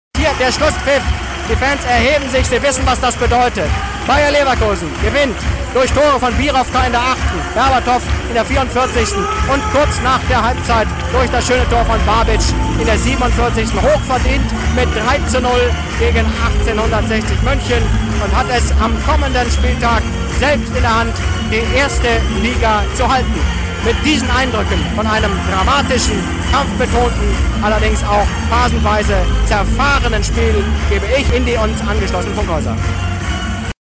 Hier finden Sie eine Auswahl von selbst gesprochenen Nachrichten als Ton-Dateien (mp3-Format) und Hörfunk-Manuskripte.